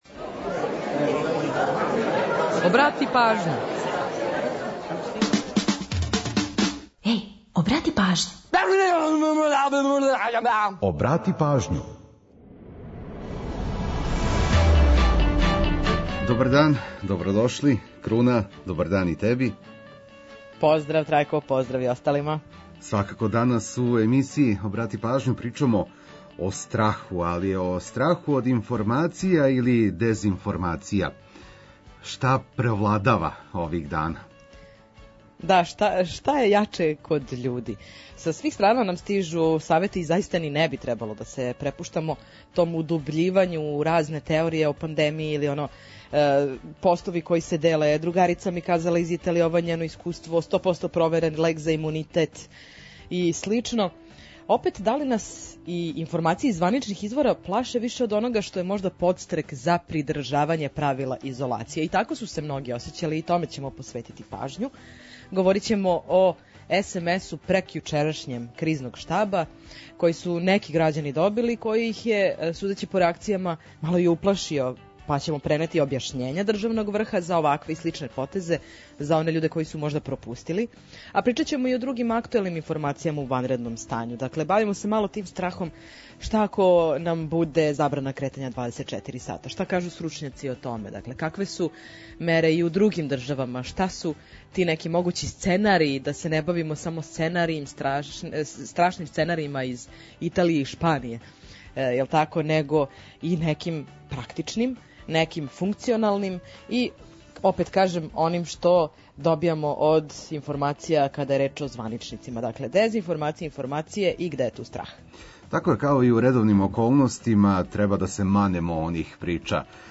Tu je i pola sata rezervisanih samo za numere iz Srbije i regiona.